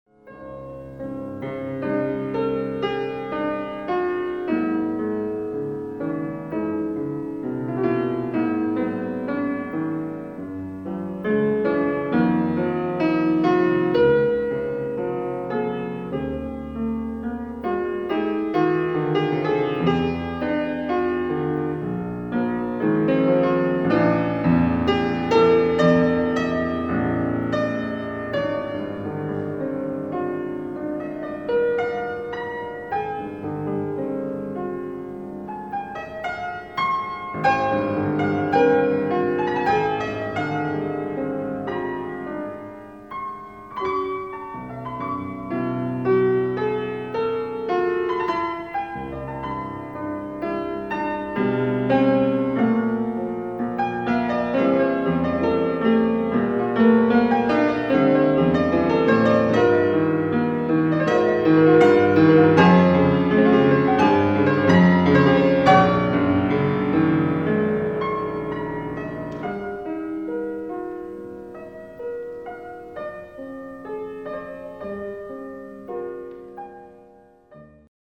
Andante